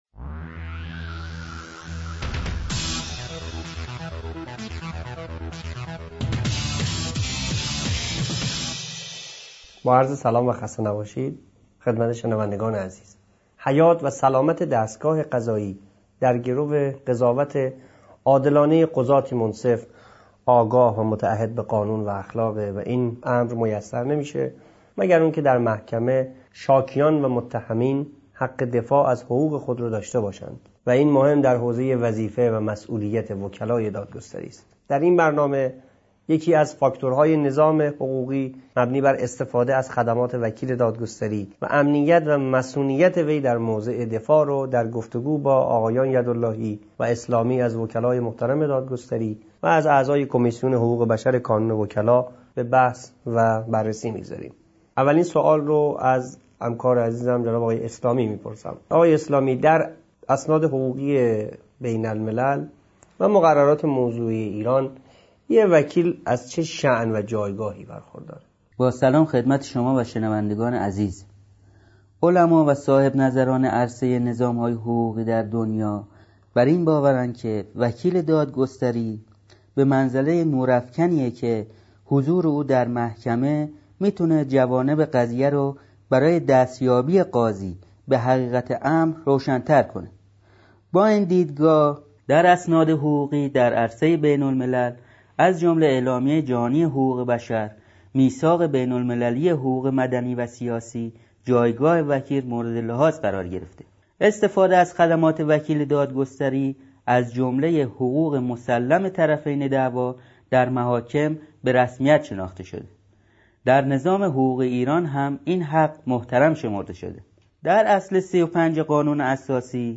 برنامه شماره دو: میز گرد حقوقی – مصونیت وکیل در مقام دفاع